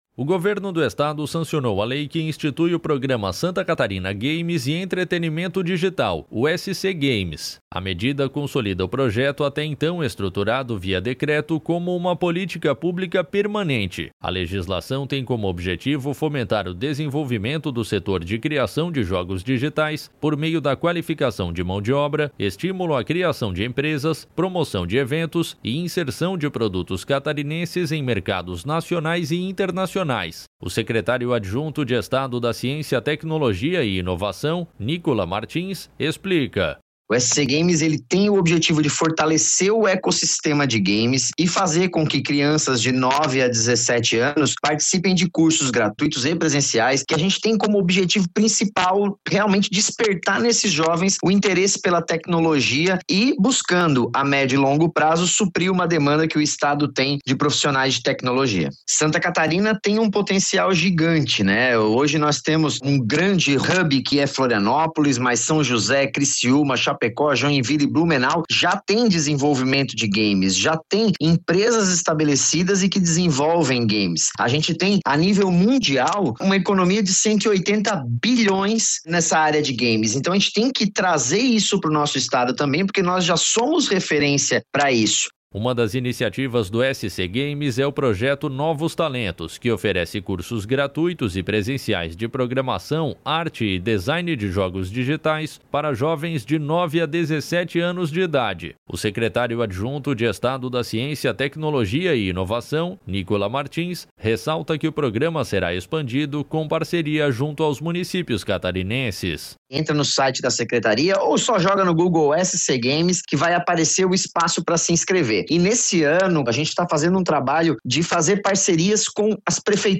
Entrevistado:
Nícola Martins – secretário adjunto de Estado da Ciência, Tecnologia e Inovação